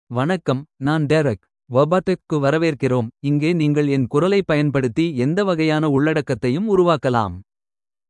Derek — Male Tamil AI voice
Derek is a male AI voice for Tamil (India).
Voice sample
Listen to Derek's male Tamil voice.
Male